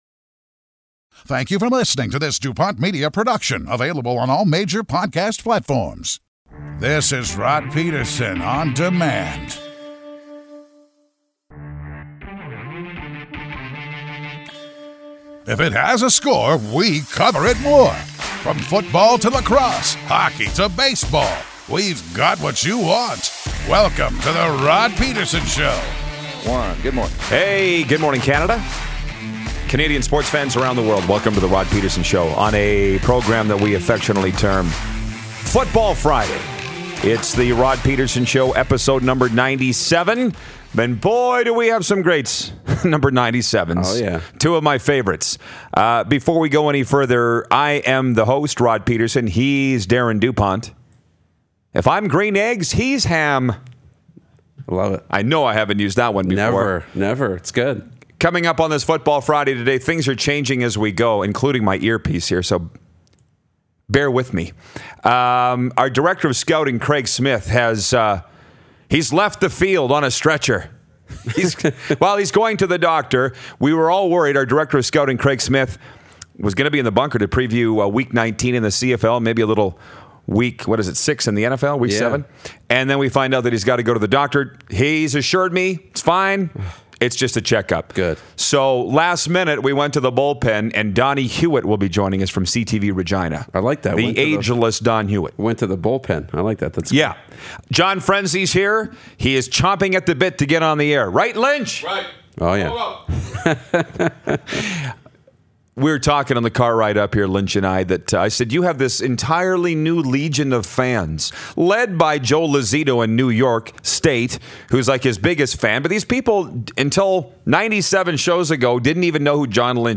joins us in studio!